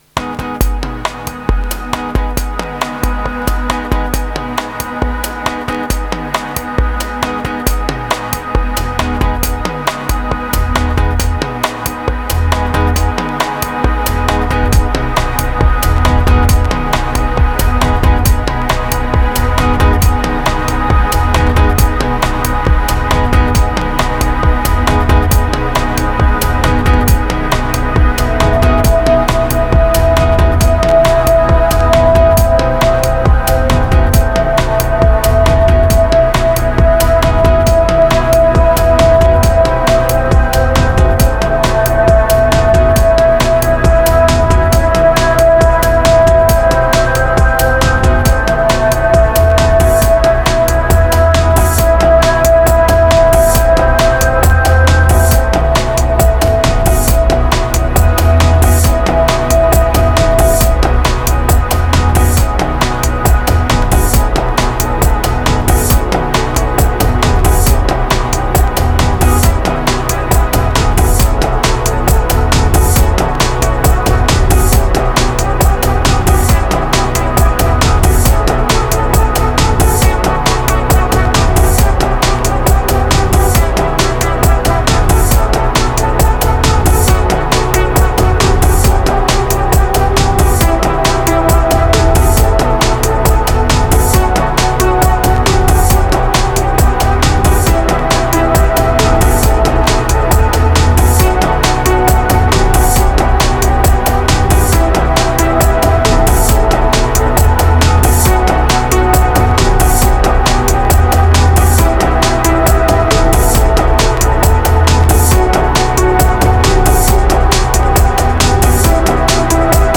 816📈 - 83%🤔 - 68BPM🔊 - 2025-08-05📅 - 687🌟
The main sample is AI generated.
Prog and static, I had doubts about this track.
Electro Progressive Bass Sparse Fetch Relief Moods Modal